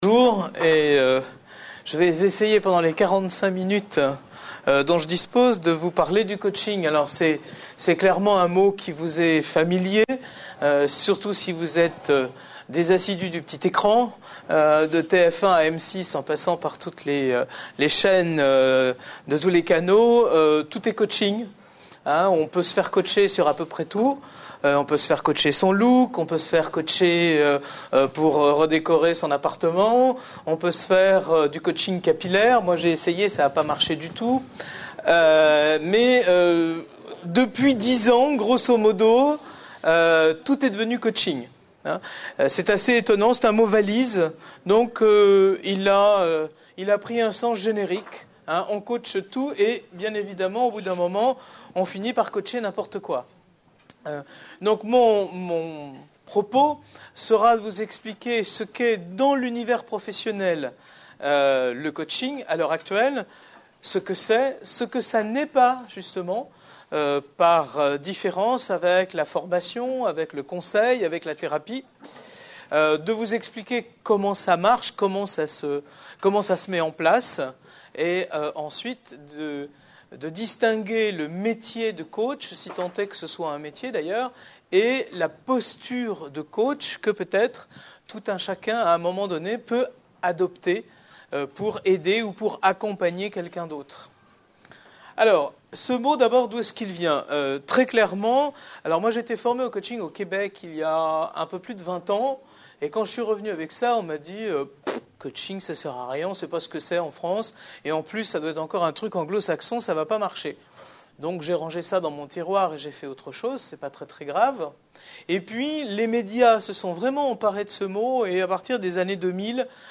Une conférence de l'UTLS au Lycée Le coaching